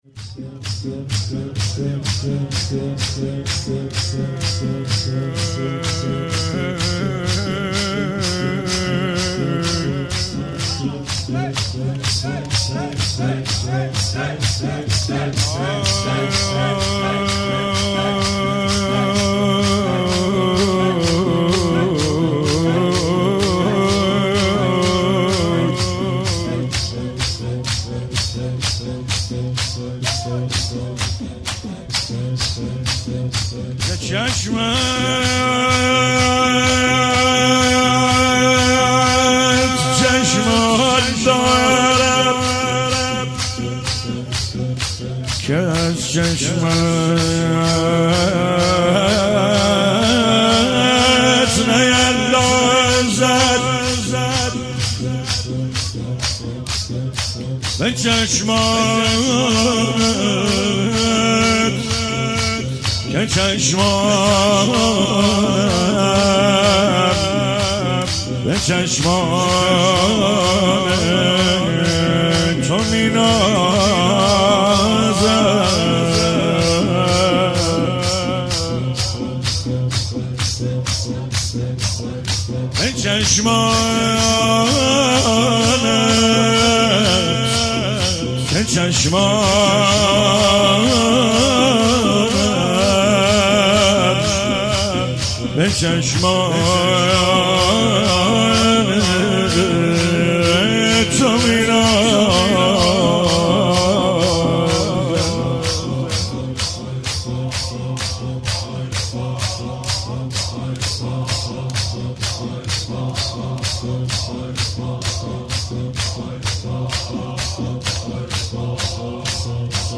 مداحی شب هفتم محرم 1399
هئيت علمدار مشهد الرضا (ع)